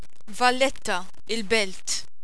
Aussprache )